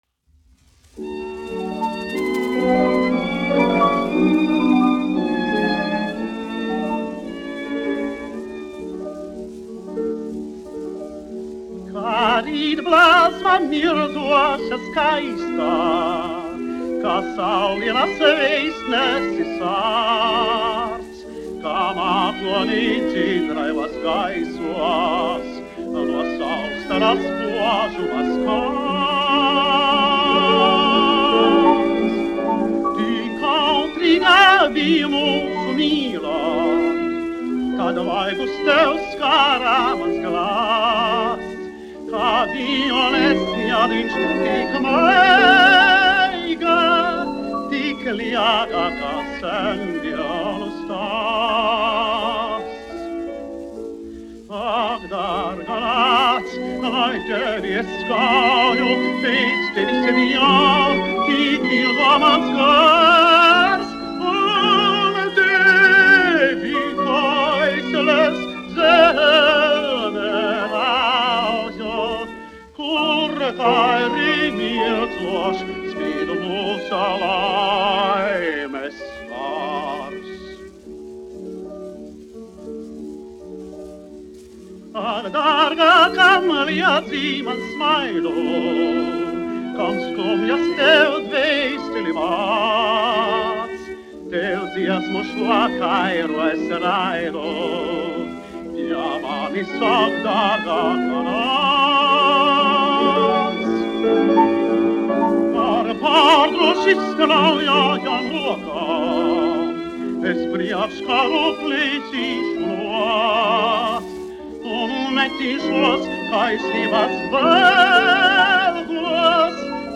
1 skpl. : analogs, 78 apgr/min, mono ; 25 cm
Dziesmas (augsta balss) ar instrumentālu ansambli